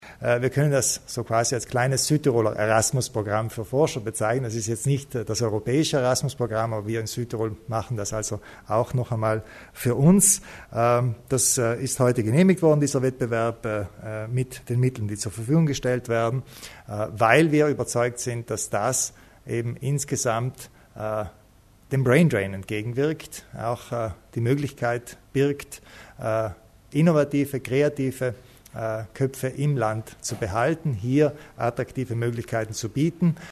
Landeshauptmann Kompatscher zum Wettbewerb für die internationale Mobilität von Forschern